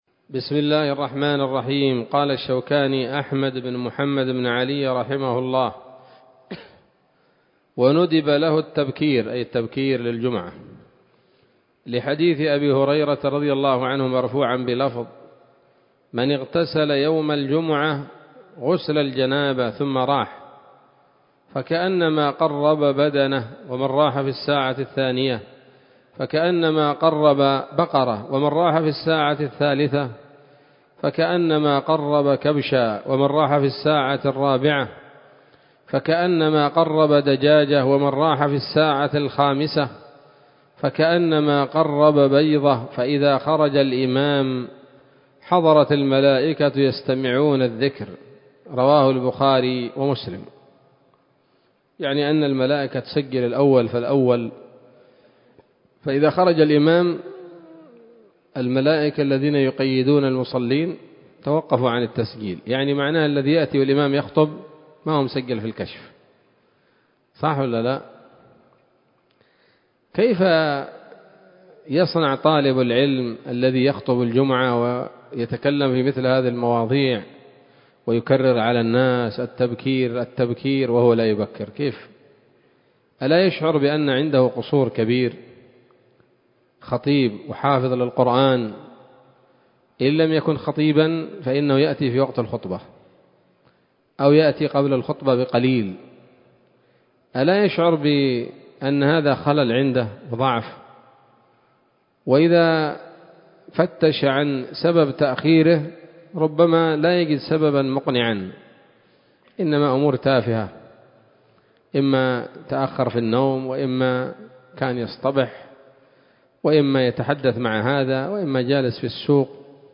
الدرس الرابع والأربعون من كتاب الصلاة من السموط الذهبية الحاوية للدرر البهية